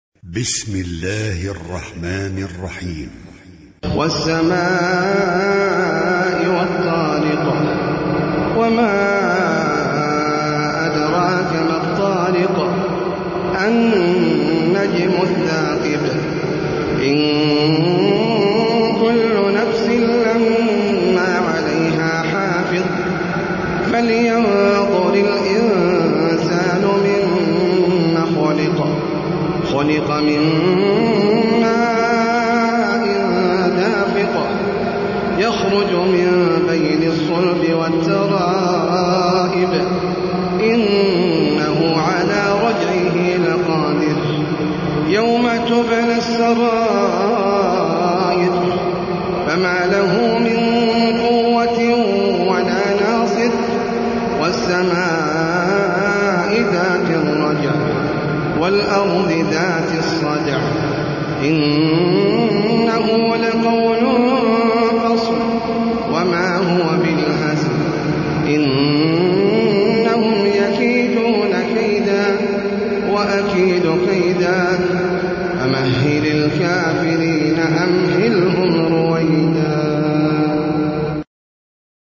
سورة الطارق - المصحف المرتل (برواية حفص عن عاصم)
جودة عالية